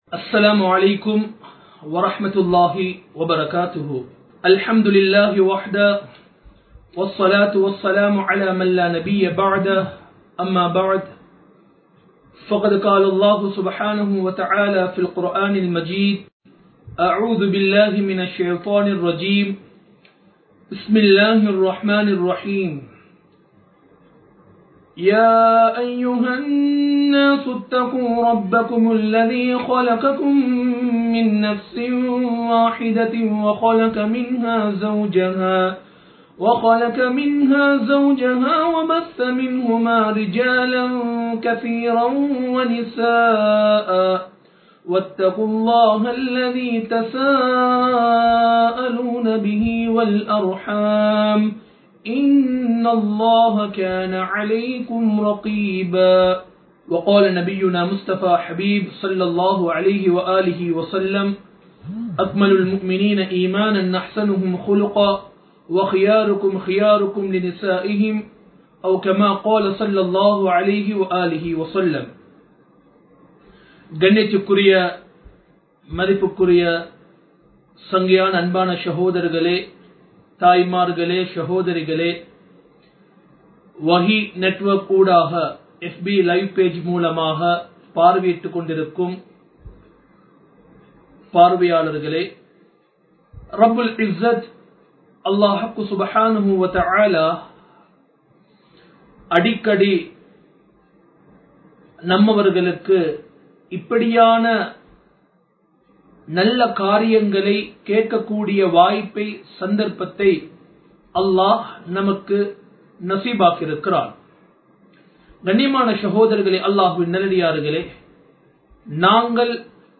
Kudumba Vaalkai (குடும்ப வாழ்க்கை- பகுதி 1) | Audio Bayans | All Ceylon Muslim Youth Community | Addalaichenai
Live Stream